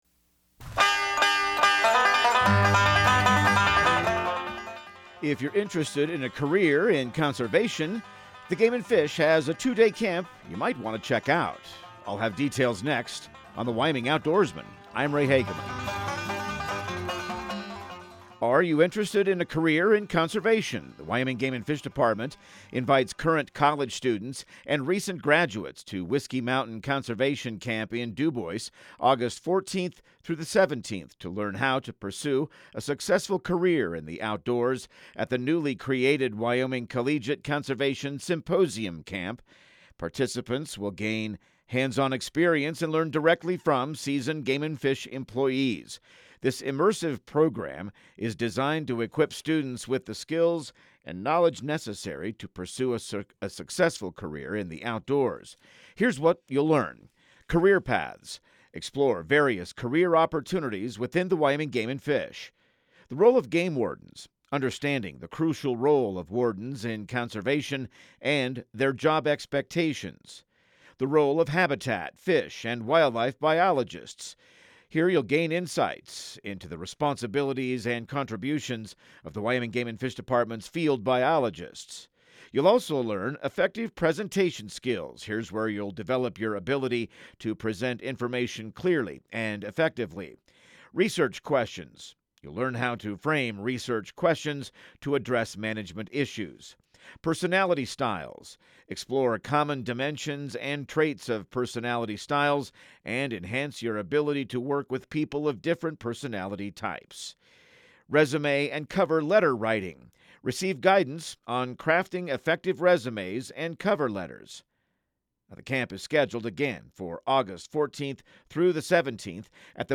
Radio news | Week of June 16